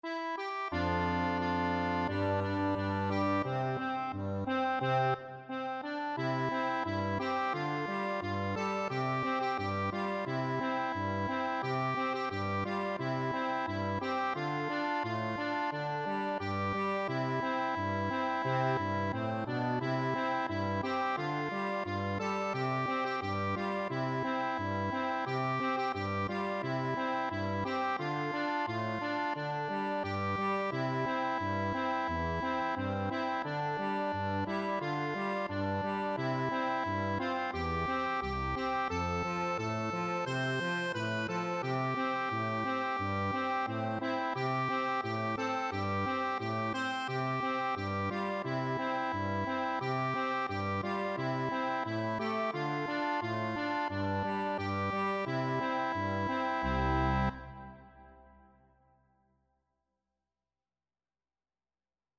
Pop Trad. Muss i denn (aka Wooden Heart) Accordion version
4/4 (View more 4/4 Music)
C major (Sounding Pitch) (View more C major Music for Accordion )
Andante =c.88
Accordion  (View more Easy Accordion Music)
Traditional (View more Traditional Accordion Music)
Bavarian Music for Accordion
muss_i_denn_ACC.mp3